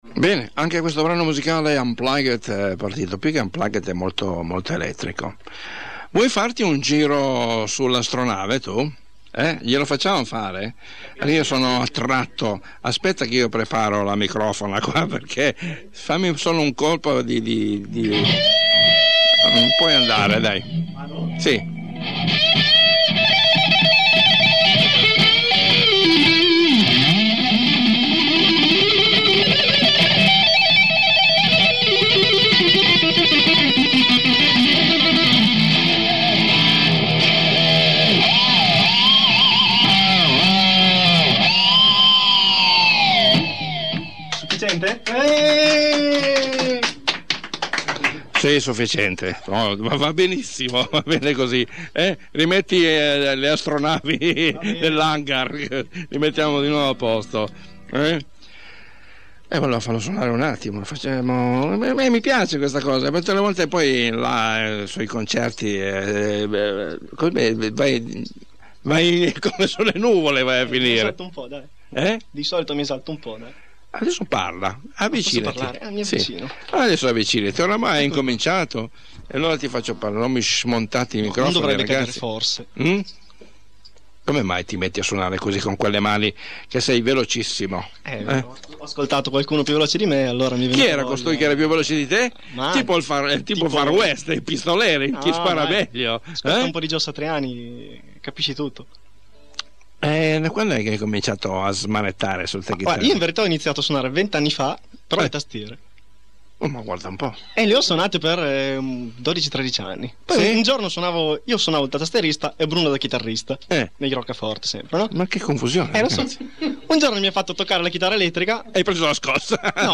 In diretta da Radio Italia 1 di Torino, Live acustico